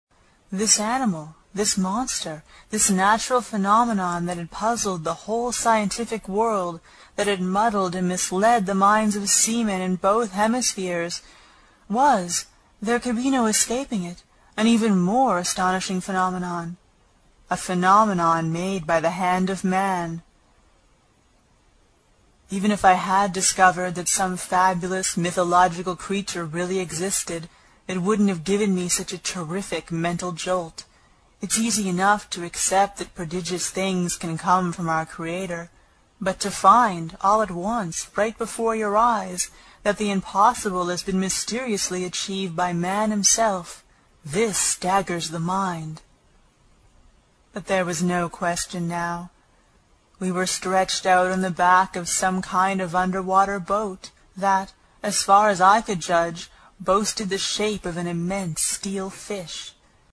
英语听书《海底两万里》第90期 第7章 一种从未见过的鱼(13) 听力文件下载—在线英语听力室
在线英语听力室英语听书《海底两万里》第90期 第7章 一种从未见过的鱼(13)的听力文件下载,《海底两万里》中英双语有声读物附MP3下载